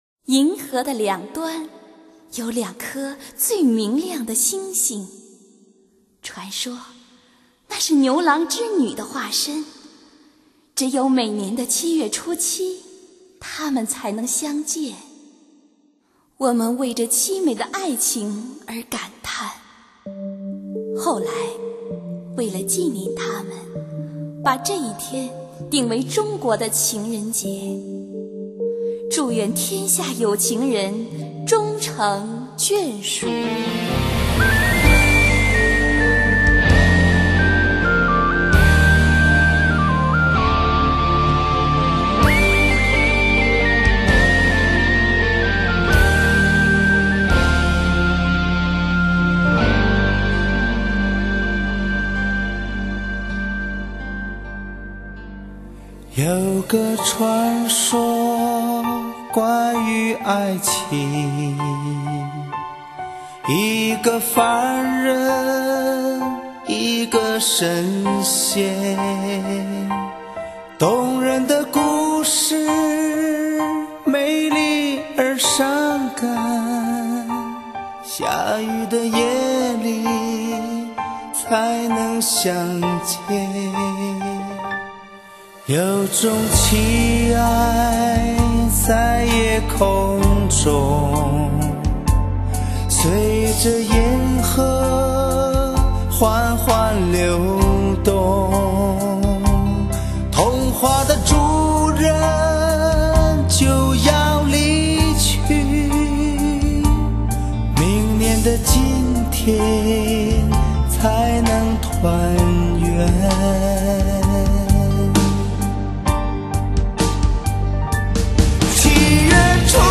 深情的豪迈嗓音
浪漫的夜晚需要温暖的情歌相随
低品质64k/wma